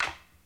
TC Clap Perc 09.wav